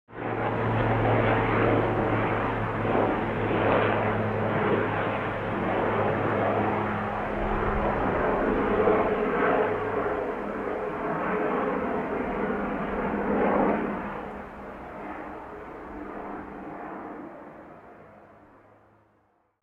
دانلود آهنگ هلیکوپتر 1 از افکت صوتی حمل و نقل
دانلود صدای هلیکوپتر 1 از ساعد نیوز با لینک مستقیم و کیفیت بالا
جلوه های صوتی